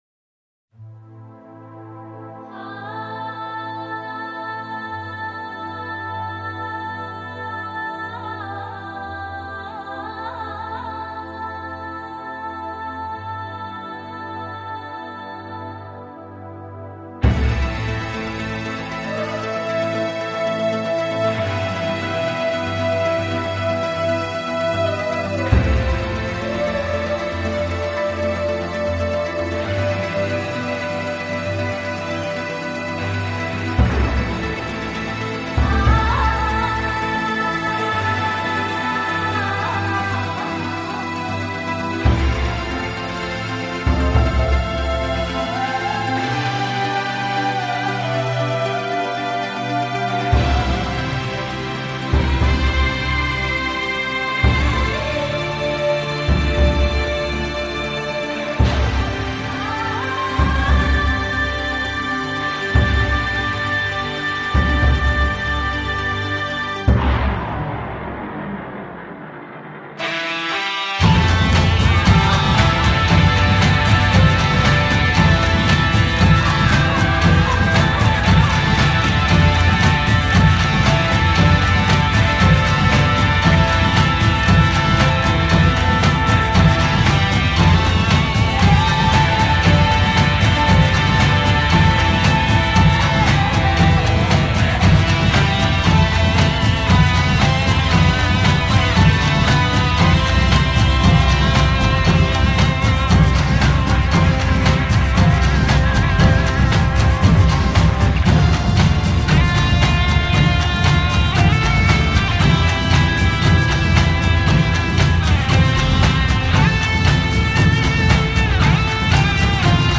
鼓声是人们直接体会声音根本的一种媒介。